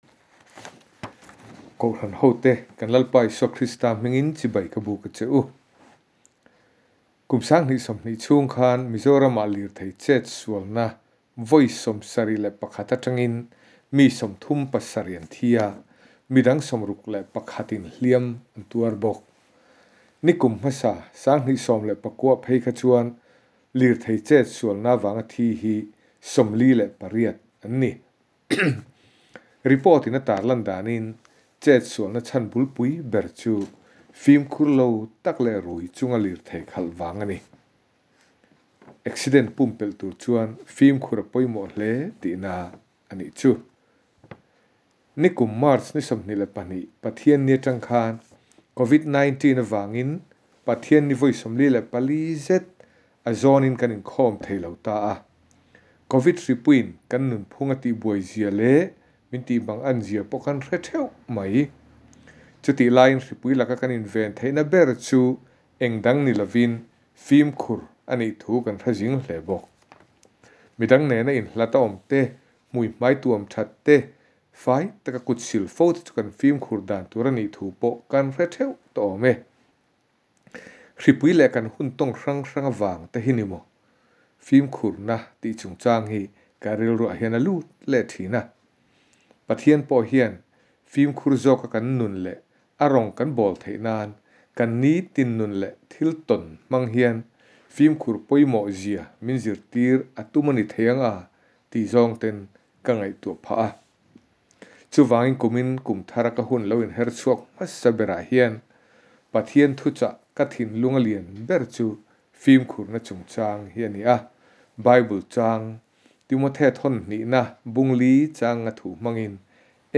THUCHAH